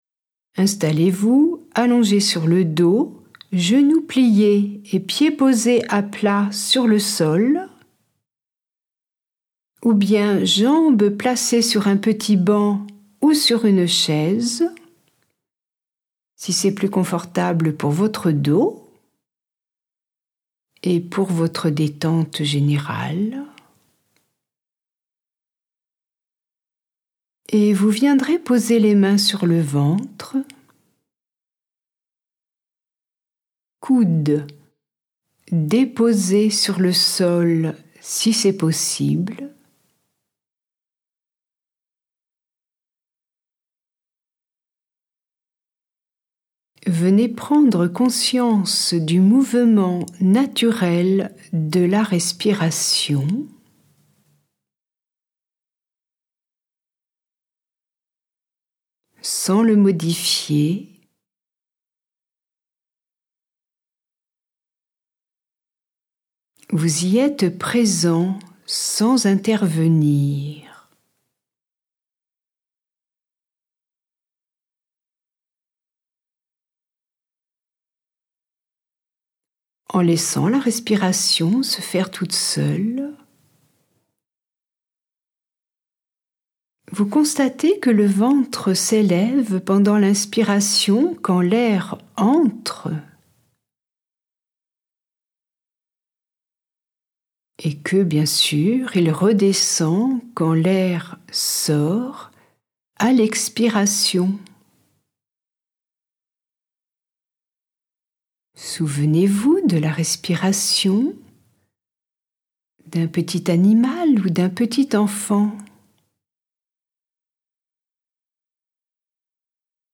pour rétablir le mouvement naturel du diaphragme. Je vous accompagne avec un exercice audio.
Exercice audio pour pratiquer chez soi